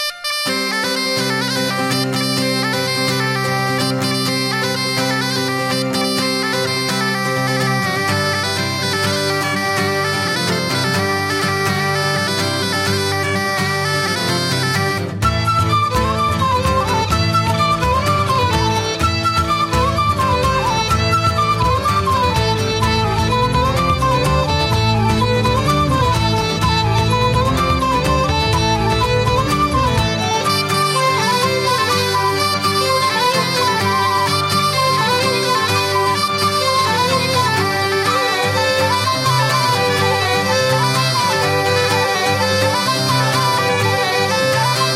Folk / Celta/ World Music
camiñando polos vieiros máis tradicionais da música galega